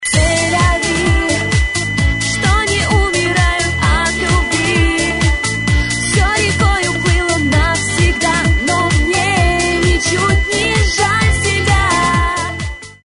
Для получения этой мелодии в формате MP3 (с голосом)
• Пример мелодии содержит искажения (писк).